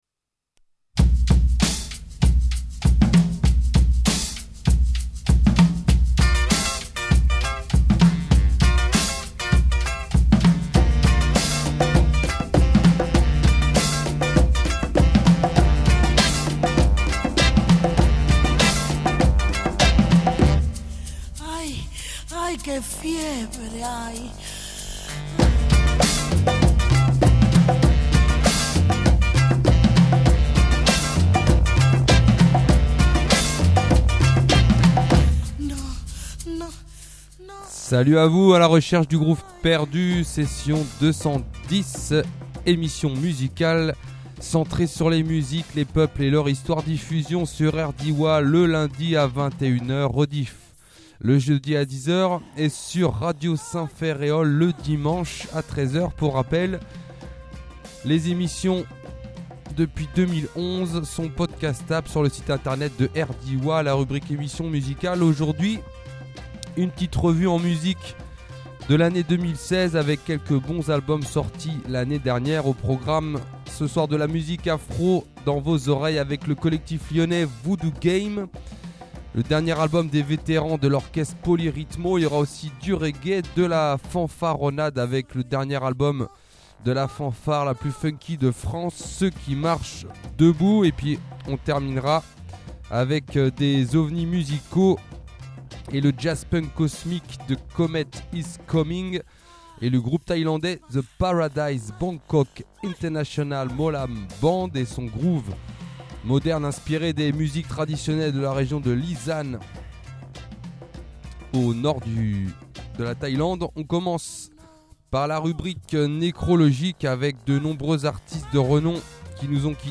funk , reggae , soul